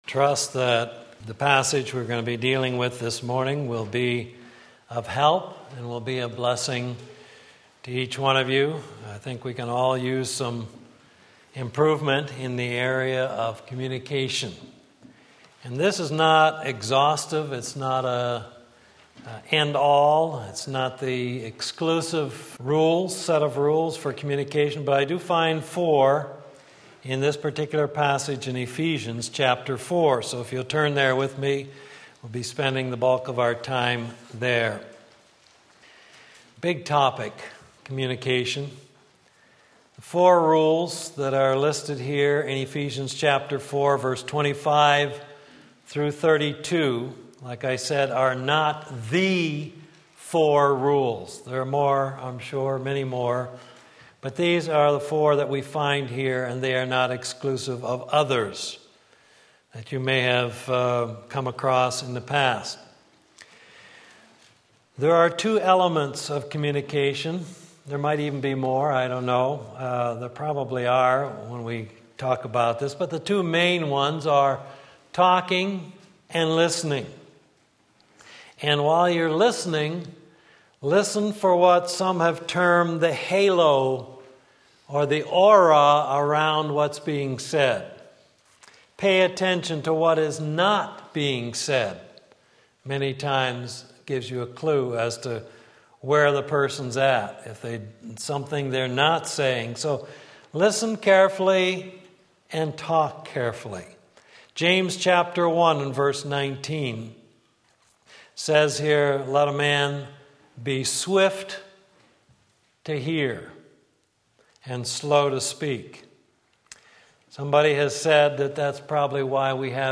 Sermon Link
Four Rules of Communication Ephesians 4:25-32 Sunday Morning Service